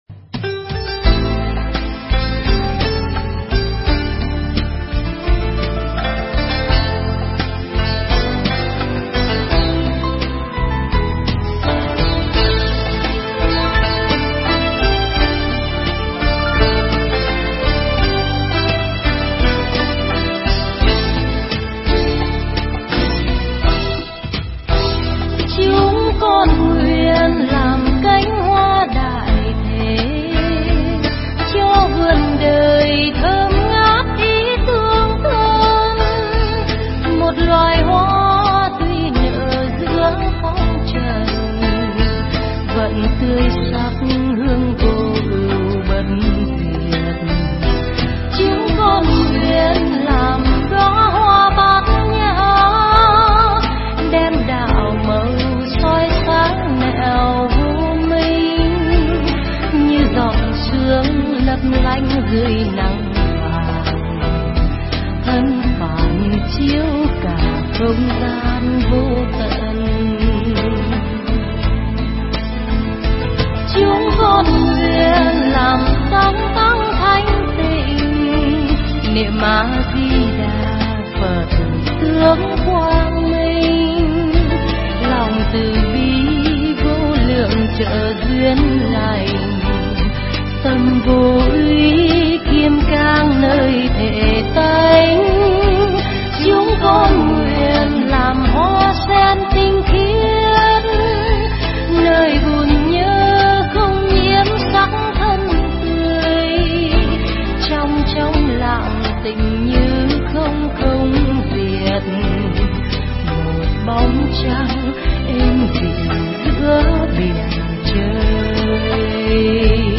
Mp3 Pháp Thoại Mỗi Người Mỗi Hạnh Tu
giảng tại Chùa Tất Viên